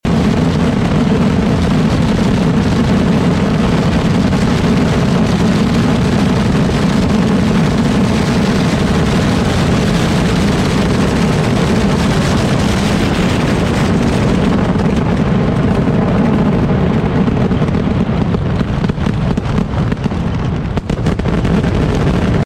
Most Extreme Fireworks Ever 💥 Sound Effects Free Download